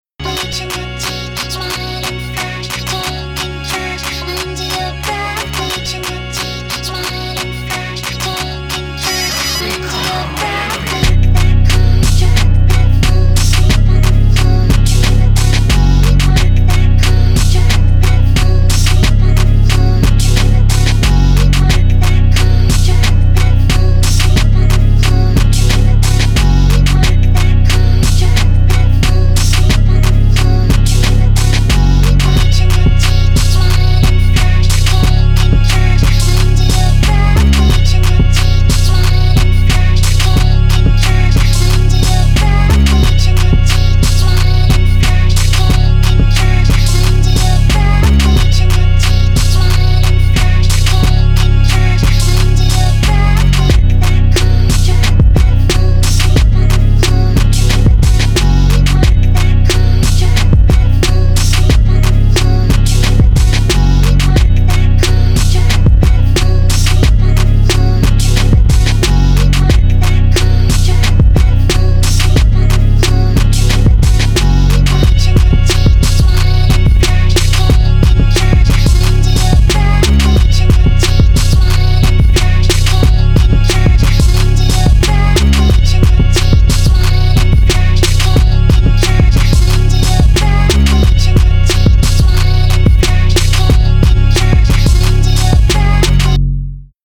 eddit - it’s a rock demo